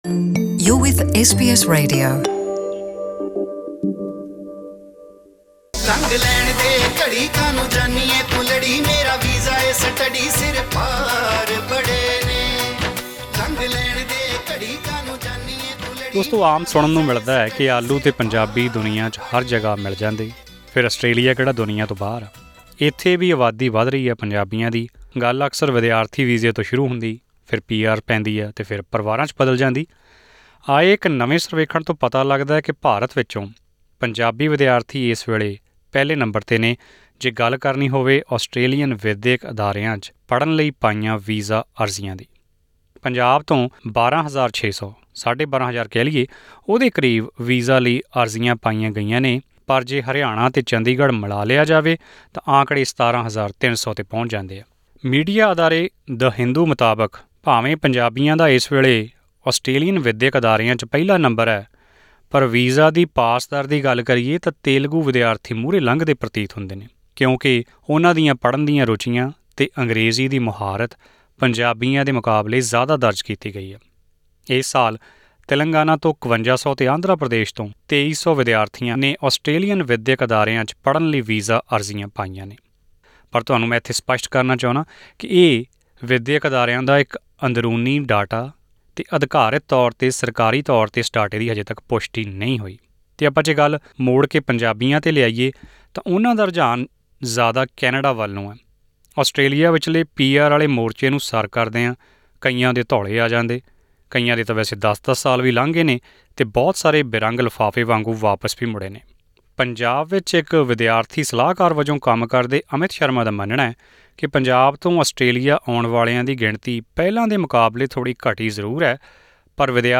Listen to this audio report for more information…